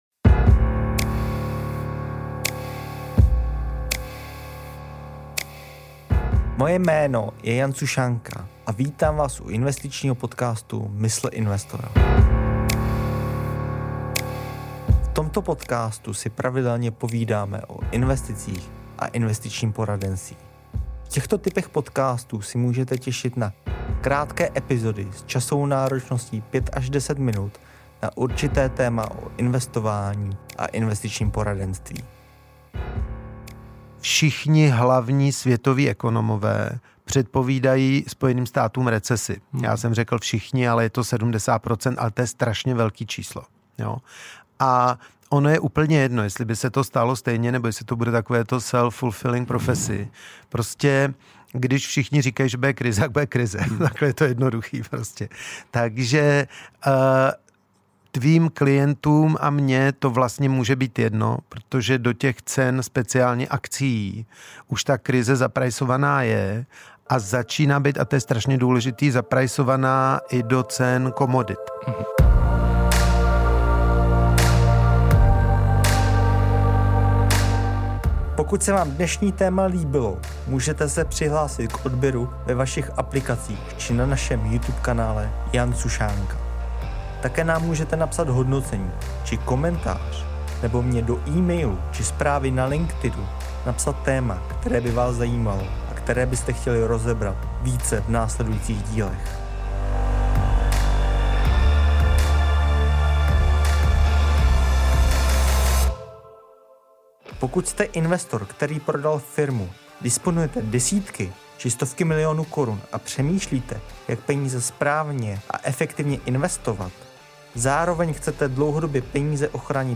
Tento rozhovor je vhodný pro lidi, kteří se zabývají investováním do akcií a řeší problematiku finanční nezávislosti a pasivního příjmu a současné situace na trhu.